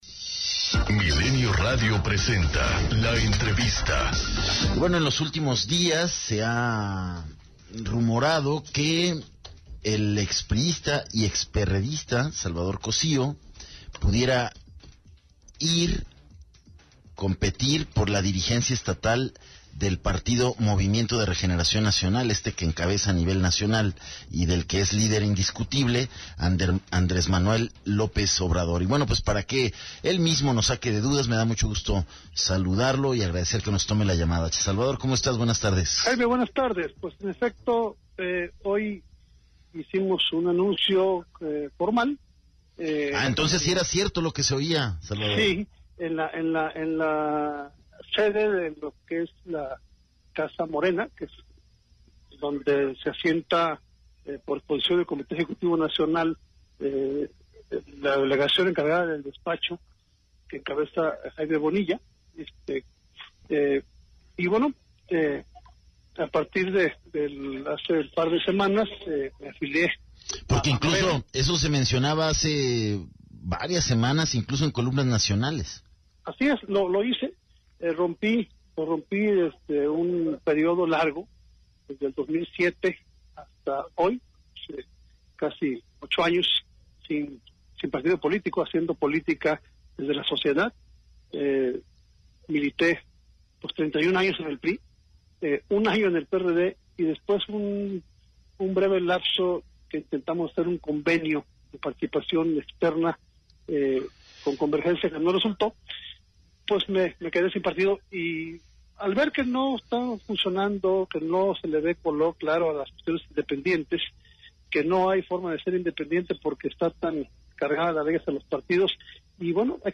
ENTREVISTA 040915